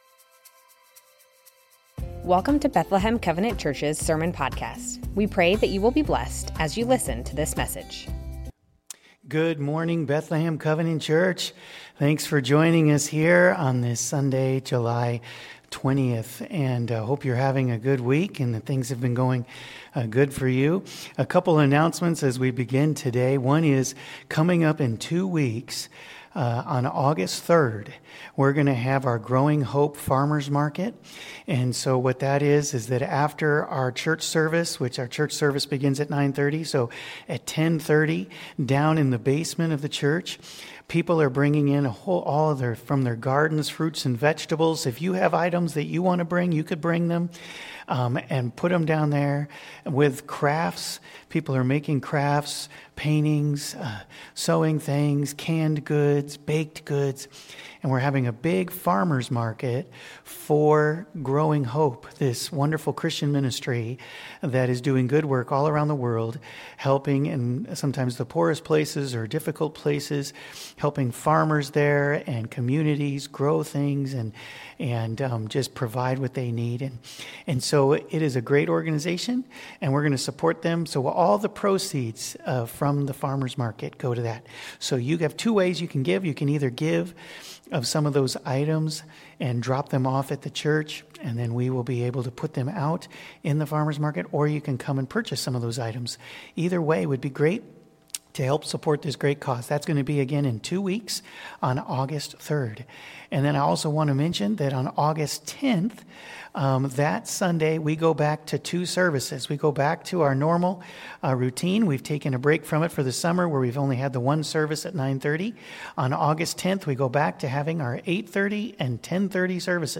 Bethlehem Covenant Church Sermons Matthew 23 - Don't be a hypocrite Jul 20 2025 | 00:38:19 1x 00:00 / 00:38:19 Subscribe Share Spotify RSS Feed Share Link Embed